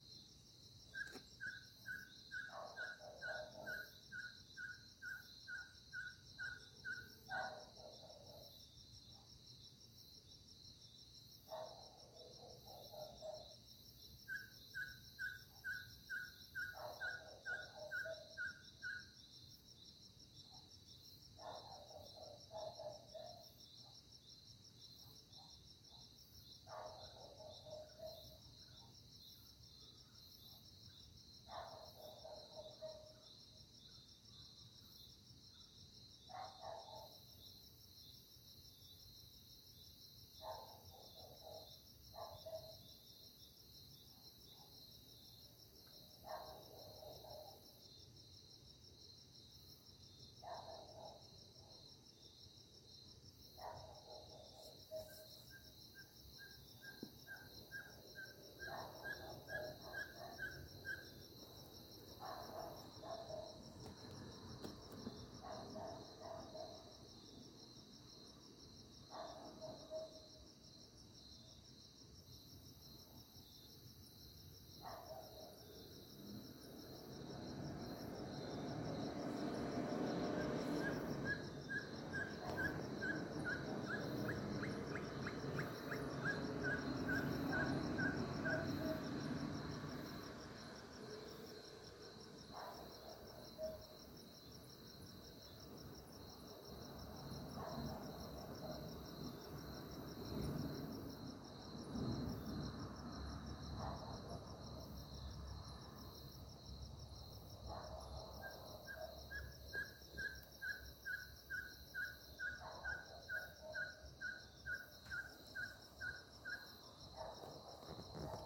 Caburé Chico (Glaucidium brasilianum)
Nombre en inglés: Ferruginous Pygmy Owl
Localidad o área protegida: San Miguel de Tucumán
Condición: Silvestre
Certeza: Vocalización Grabada